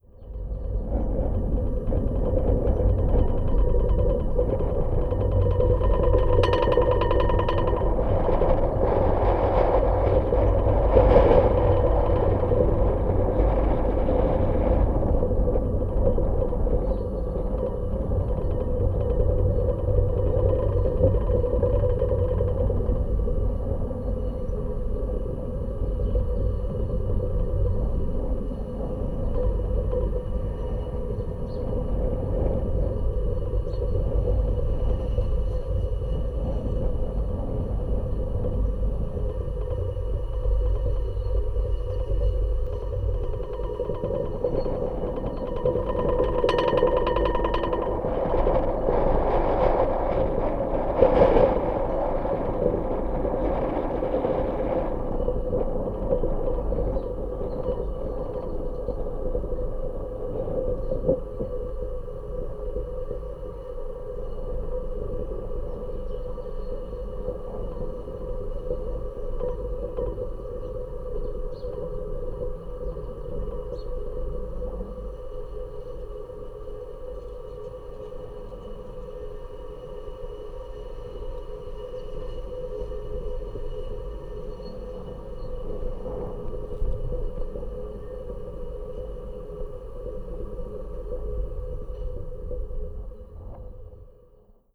Sonido interior de una pequeña turbina eólica en funcionamiento.
[ENG] Interior sound of a small wind turbine